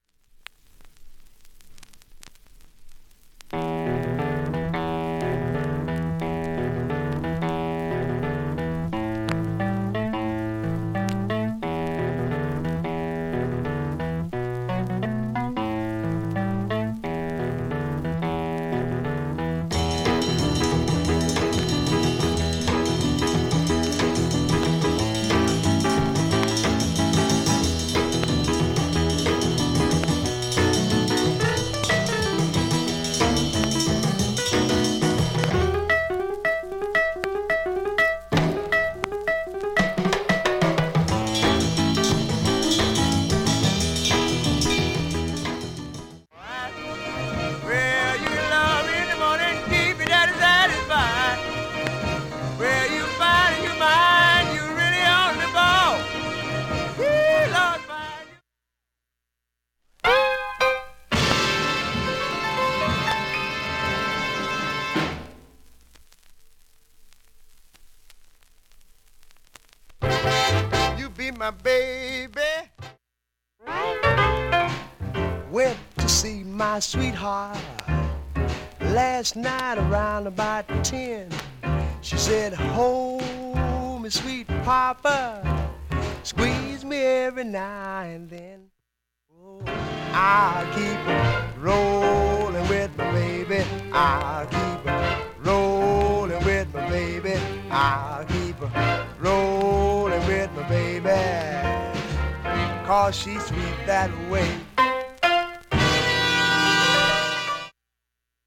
音質良好全曲試聴済み。
1,A-1始めにかすかなプツが1回と9回出ます。
2,(47s〜)A-2序盤にかすか3回プツ
3,(59s〜)A-2終わりに２回プツ出ます。
4,(1m13s〜)B-2序盤にかすか4回プツ
5,(1m29s〜)B-2終盤にかすか7回プツ
現物の試聴（上記録音時間1m48s）できます。音質目安にどうぞ
ほか３回までのかすかなプツが２箇所
単発のかすかなプツが１０箇所